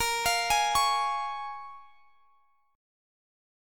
A#m7 Chord
Listen to A#m7 strummed